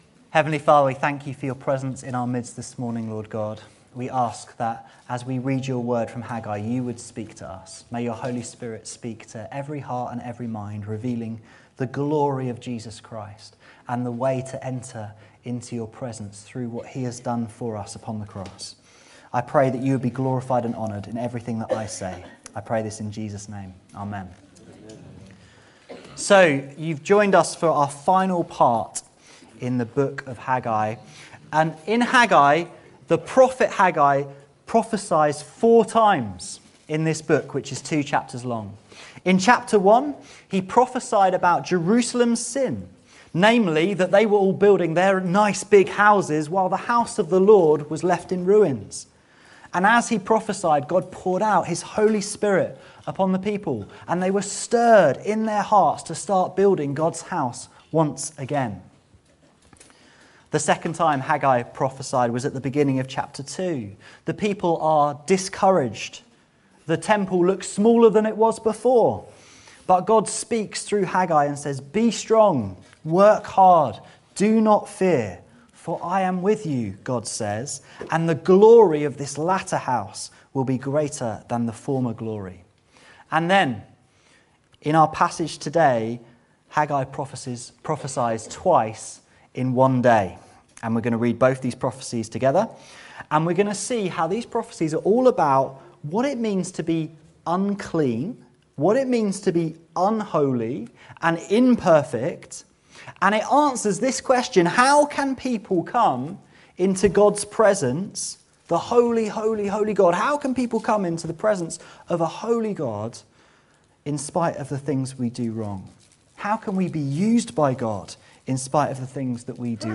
This sermon addresses the key theme of holiness through these verses.